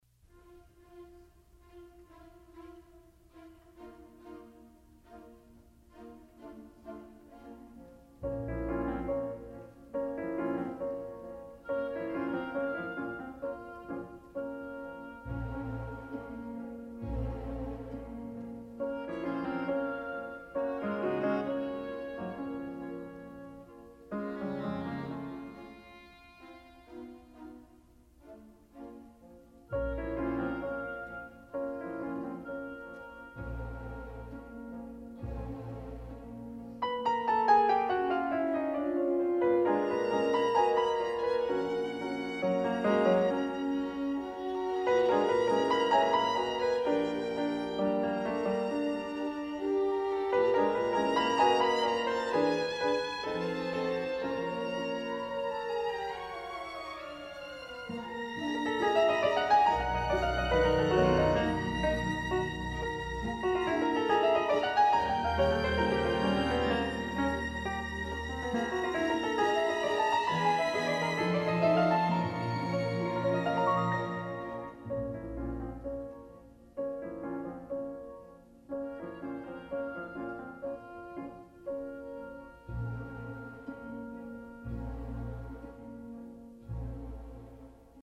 小快板，双簧管奏优美的旋律，钢琴奏主题片断